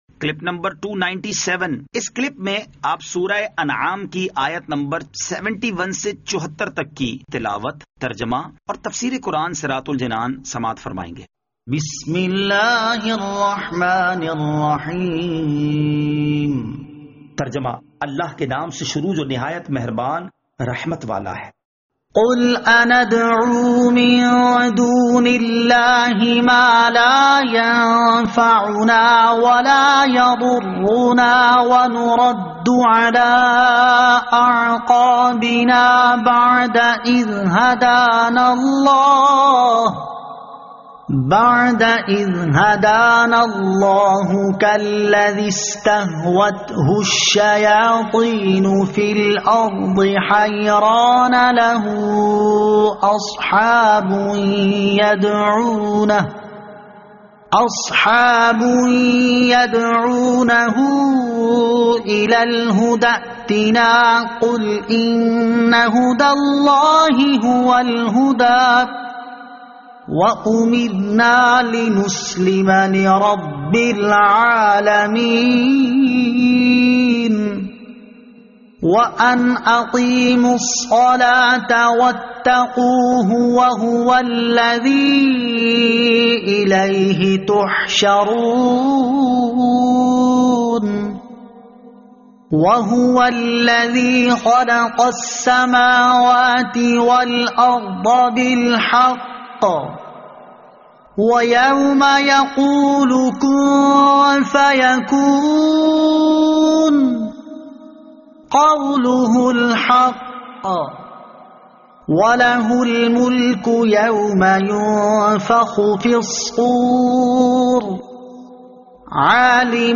Surah Al-Anaam Ayat 71 To 74 Tilawat , Tarjama , Tafseer